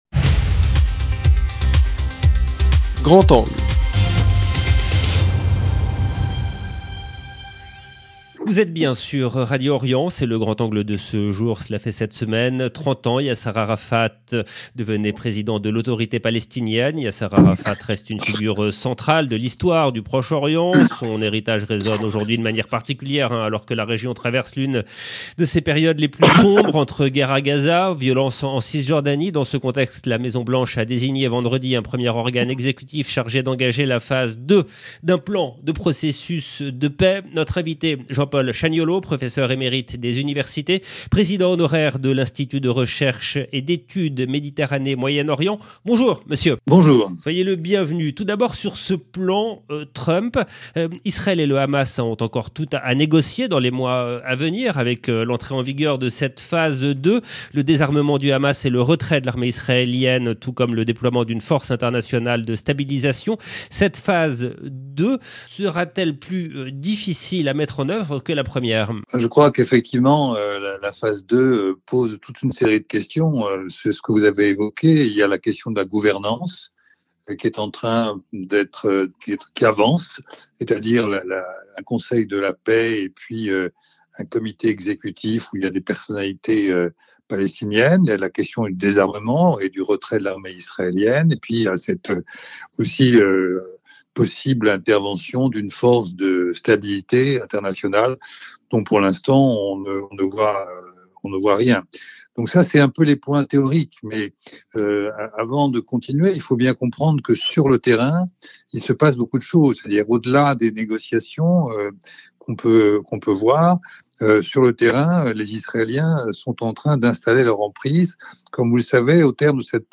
Eclairage avec notre invité